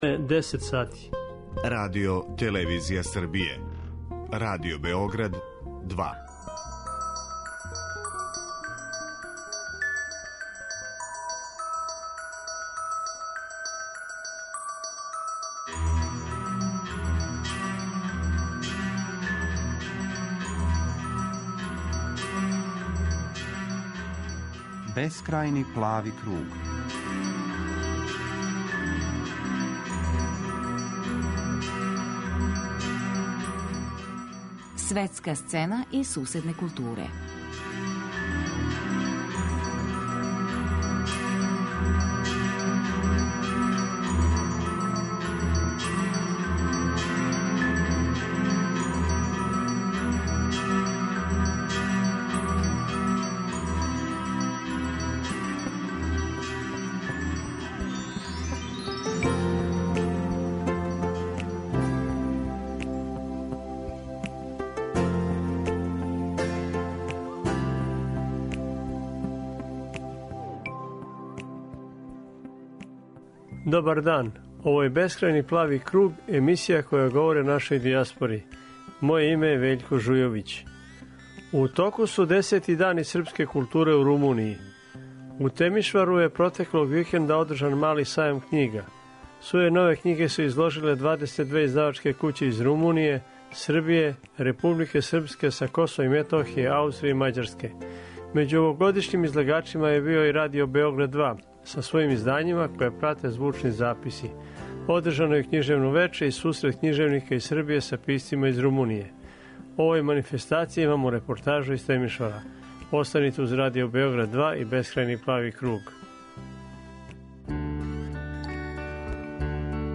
О овој манифестацији имамо репортажу из Темишвара.